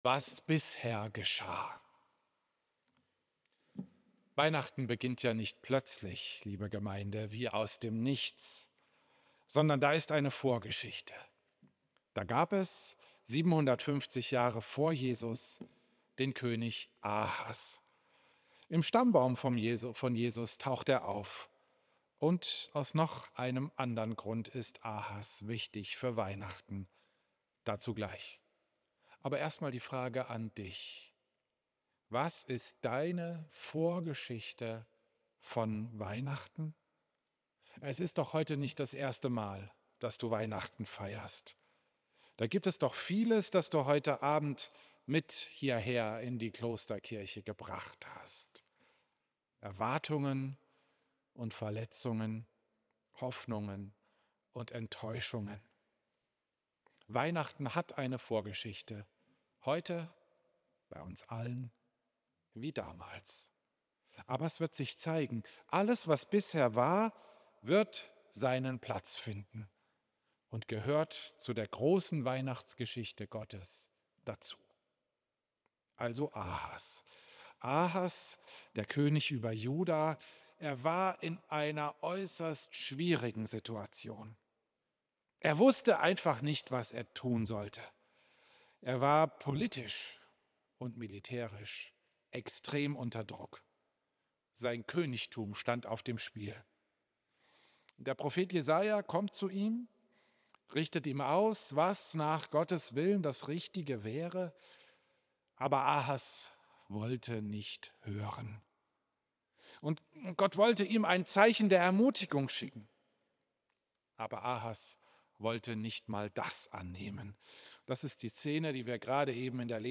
Klosterkirche Volkenroda, 24. Dezember 2025